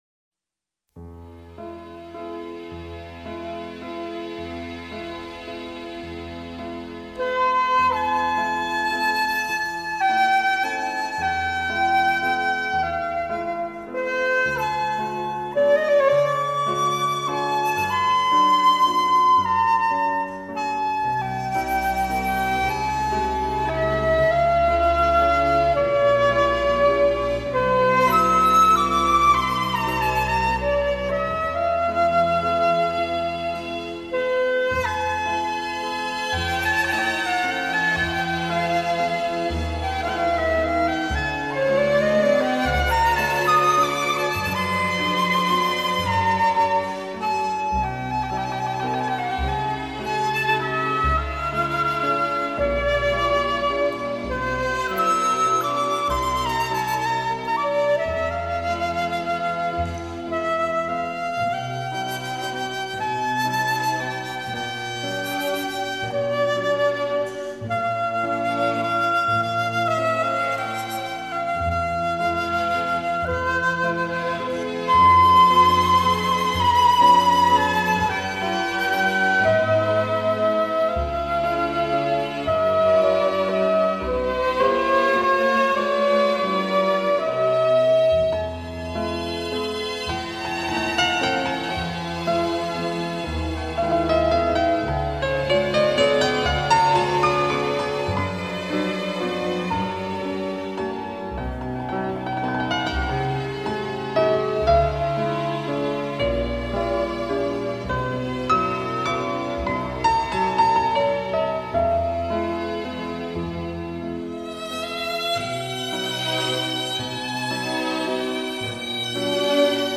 语言：纯音乐
再现出作品明朗乐观的神韵。